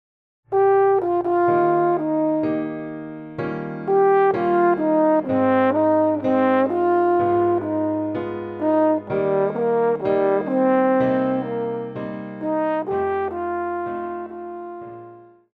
Pop
French Horn
Band
Instrumental
Rock,Country
Only backing